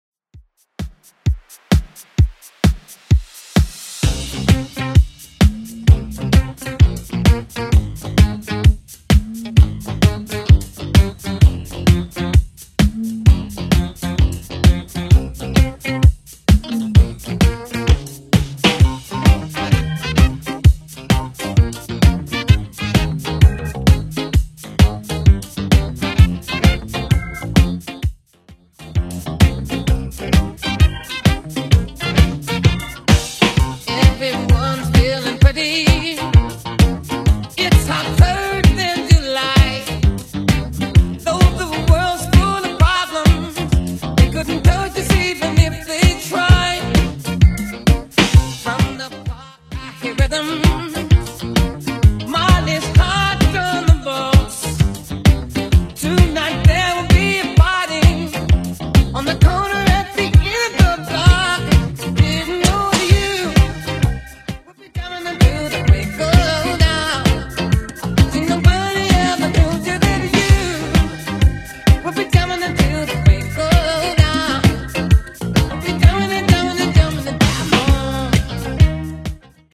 Genre: 80's
BPM: 129